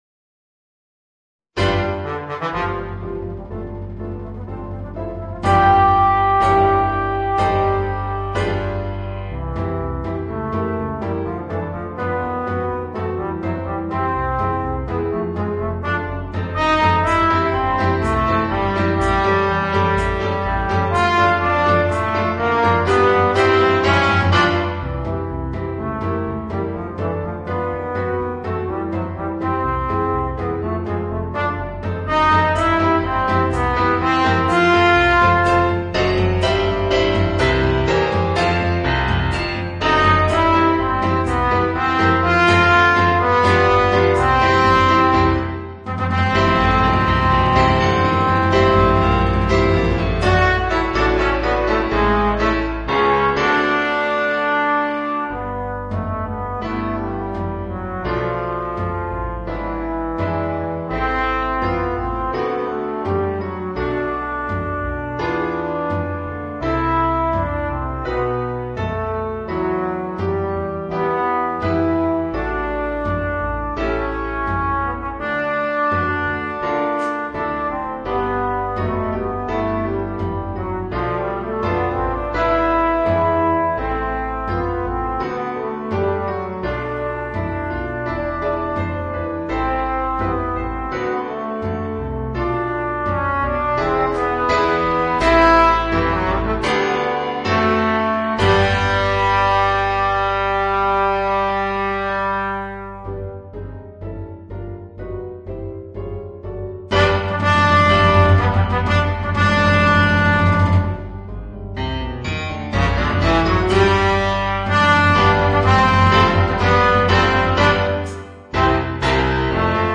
Trombone & Piano (Guitar, Bass Guitar & Drums optional)